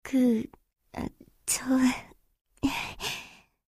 slayer_f_voc_social_04.mp3